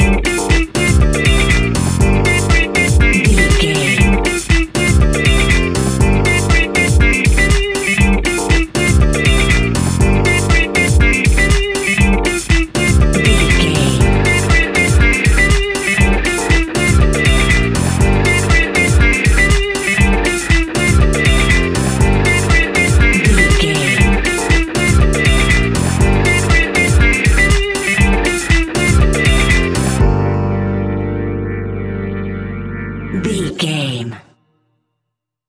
Aeolian/Minor
energetic
hypnotic
groovy
drums
bass guitar
electric guitar
electric piano
disco house
upbeat
synth leads
Synth Pads
synth bass
drum machines